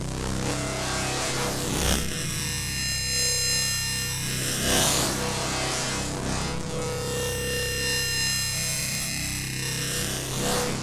sick sound from just now :wink: